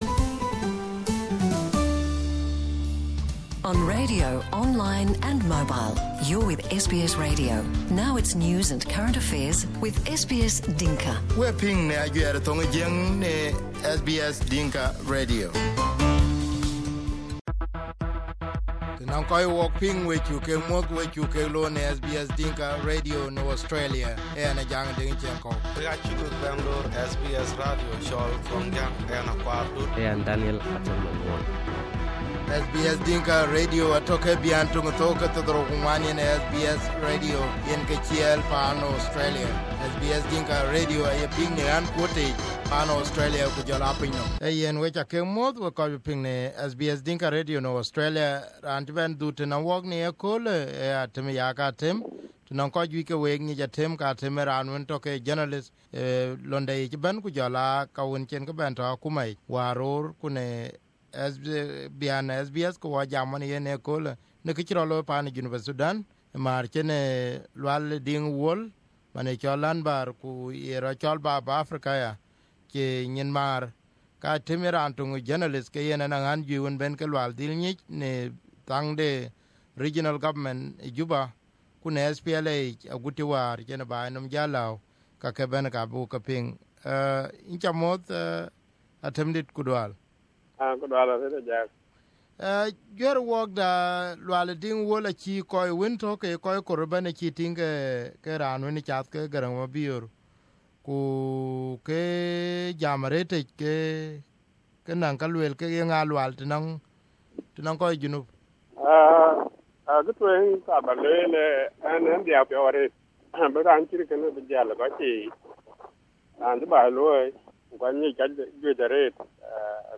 Atem Yaak Atem is a South Sudanese writer, journalist and former deputy Minister of Information in the Government of South Sudanese.
After the death of Hon Lual Diing, a veteran South Sudanese politician, Atem recalls the time he had met Lualdit and also explains the role Lual played during the liberation. Here the interview on SBS Dinka Radio.